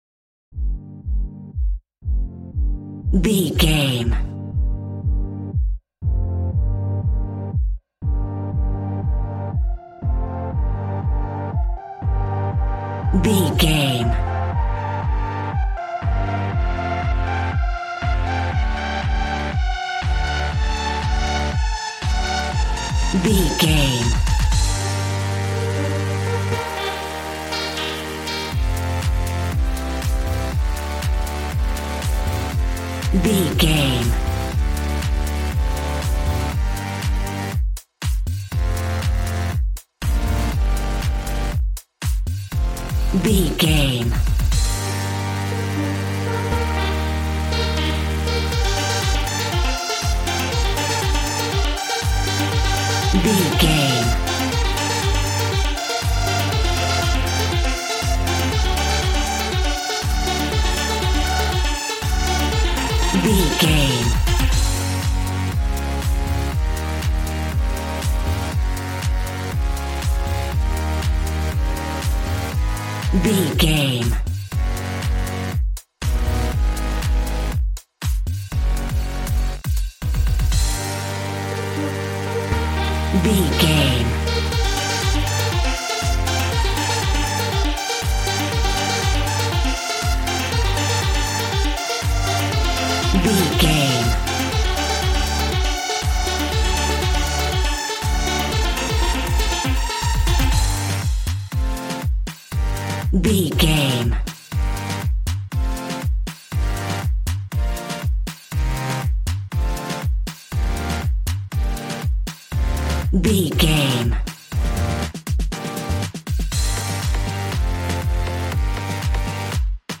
Aeolian/Minor
Fast
groovy
energetic
synthesiser
drums